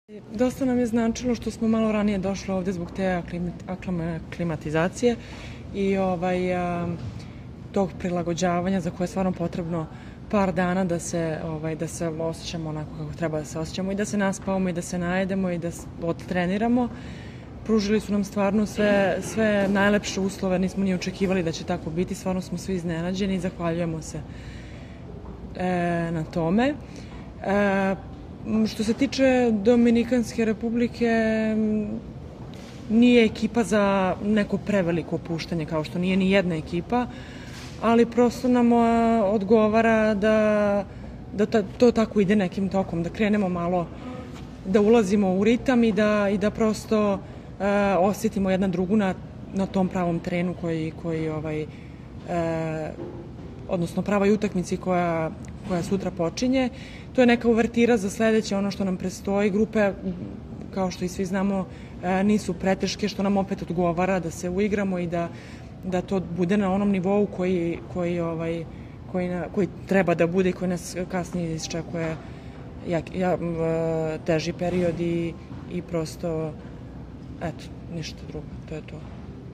Izjava Sefane Veljković